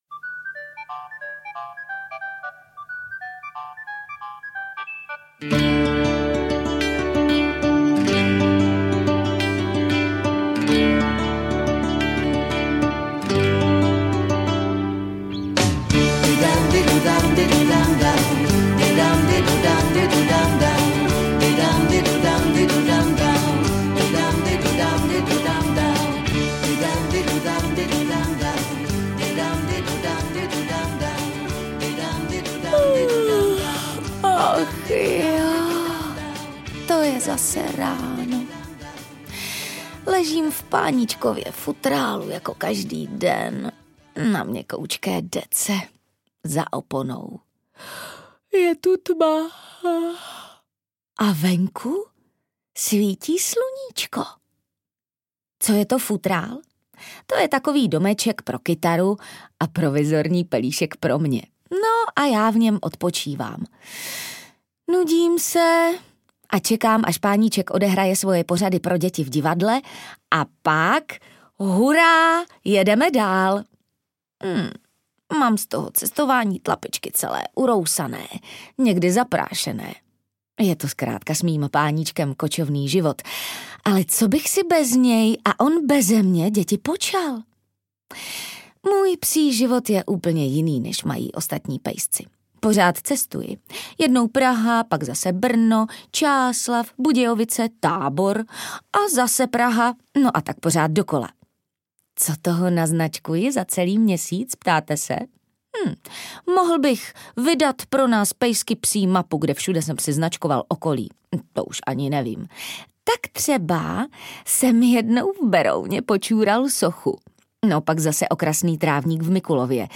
AudioKniha ke stažení, 1 x mp3, délka 59 min., velikost 54,4 MB, česky